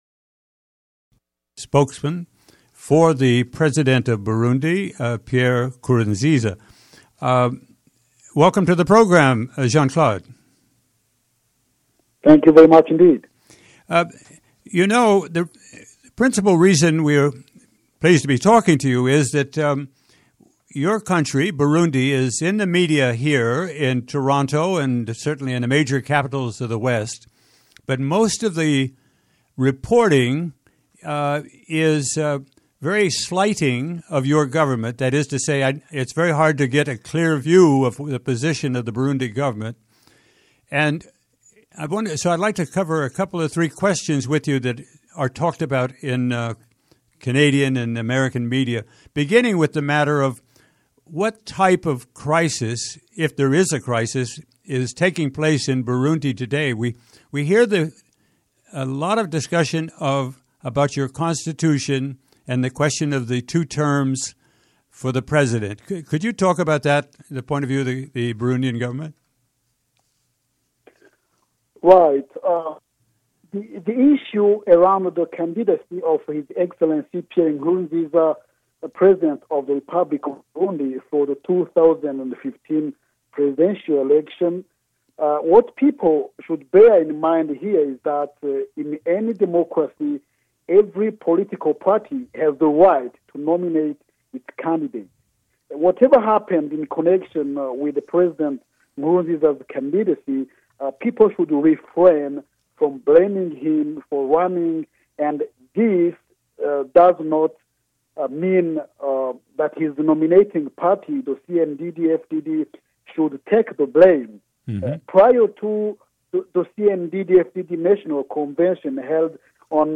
Program Type: Interview Speakers: Jean-Claude Ndenzako, spokesman for the President of Burundi